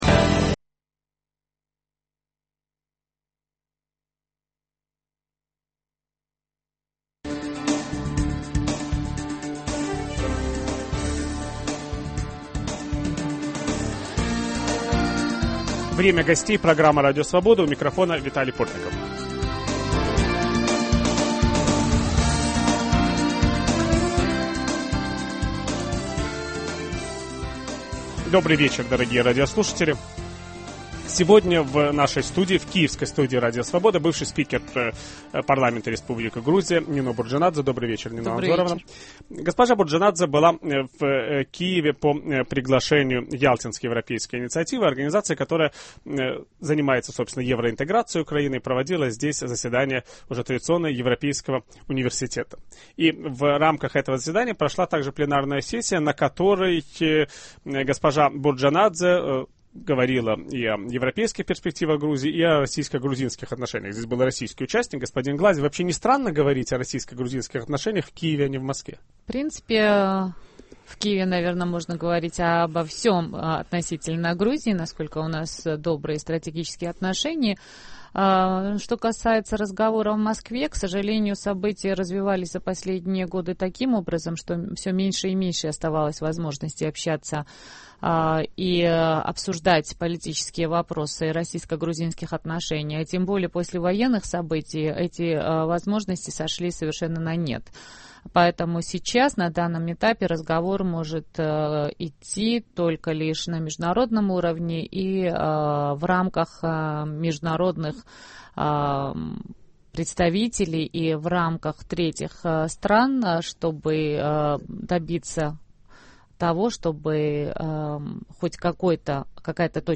Как восстановить российско-грузинские отношения? Какими должны быть взаимоотношения между грузинами, осетинами и абхазами? Об этом ведущий программы Виталий Портников беседует с бывшим председателем грузинского парламента Нино Бурджанадзе